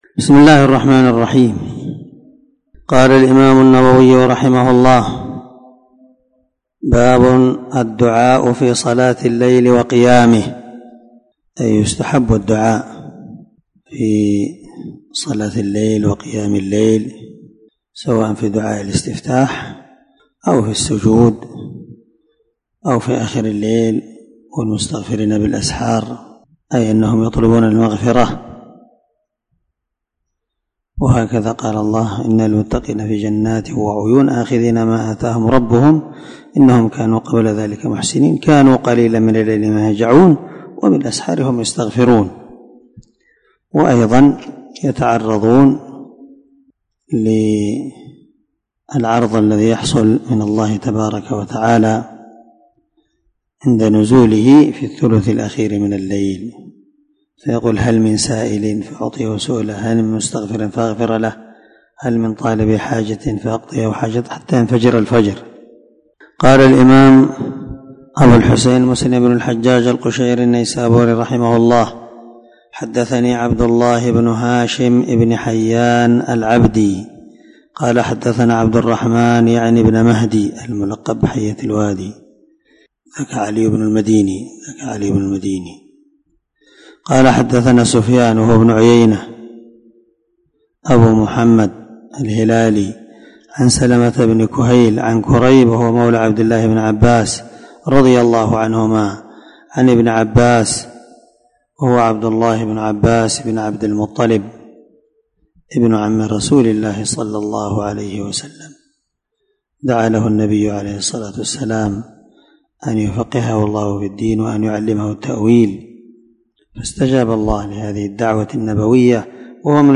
466الدرس 34 من شرح كتاب صلاة المسافرين حديث رقم ( 763_764 ) من صحيح مسلم